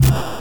24 - Enemy Kill 1